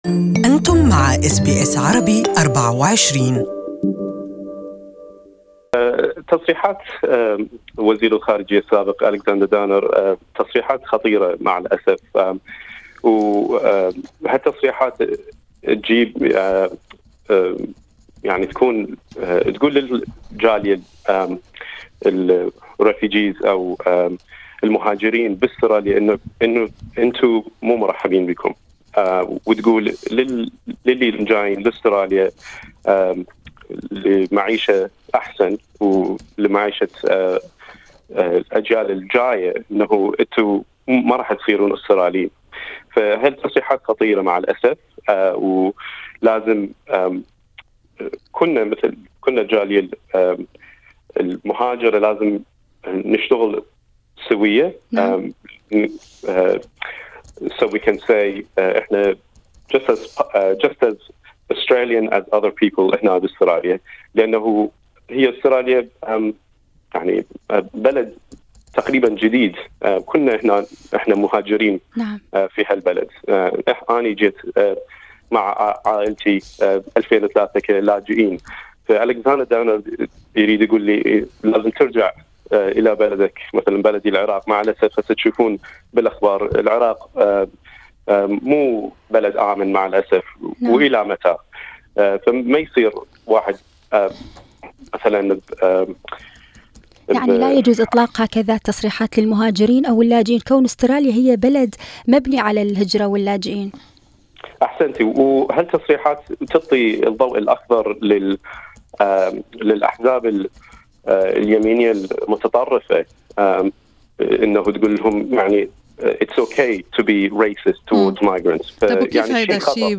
This interview is only in Arabic.